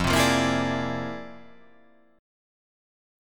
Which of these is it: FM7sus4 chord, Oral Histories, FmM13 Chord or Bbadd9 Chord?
FmM13 Chord